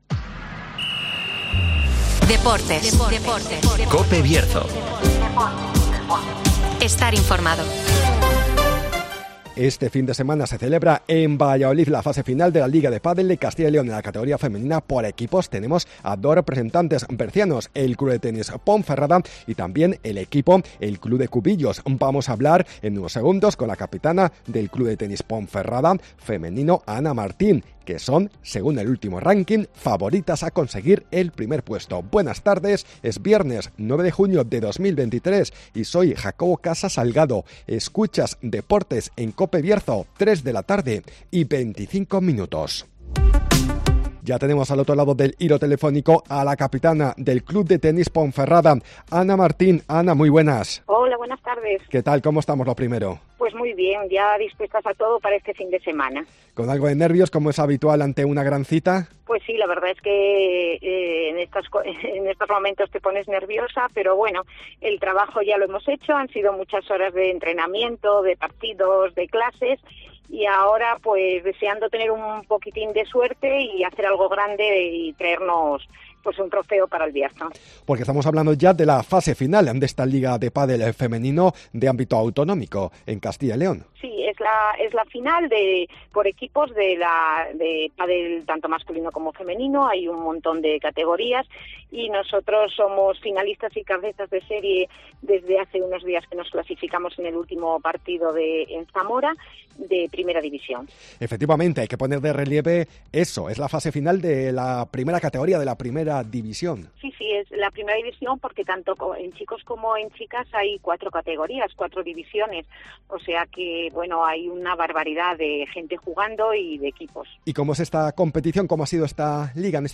DEPORTES